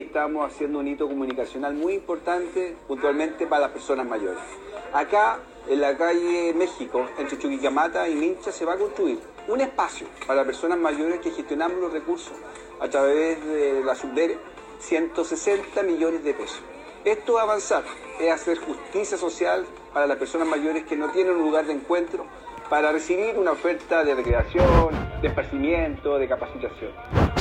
Quien encabezó la ceremonia fue el alcalde de La Calera, Johnny Piraíno que acompañado de Concejales, dirigentes y personas mayores de la ciudad, dio a conocer detalles de este ansiado proyecto comunal:
CUNA-1-ALCALDE-PIRAINO-2.mp3